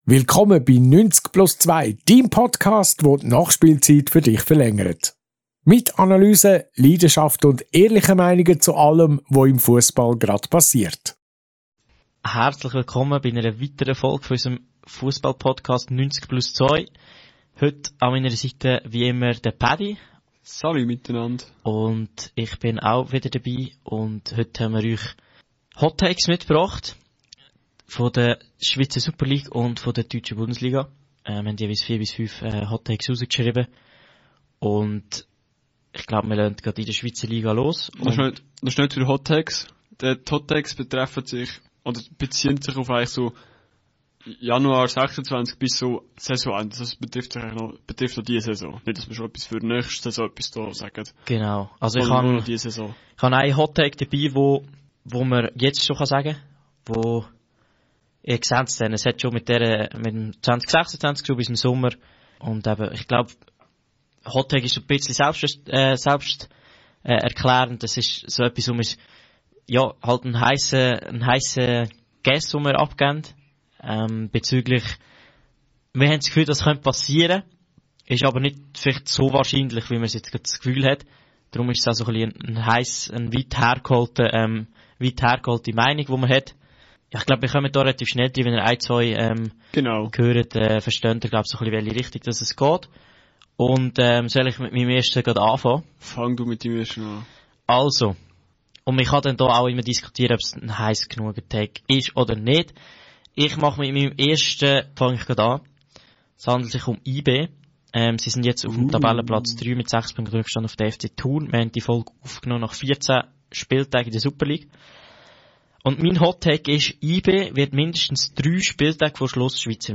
Die Folge ist locker, ehrlich und direkt gehalten.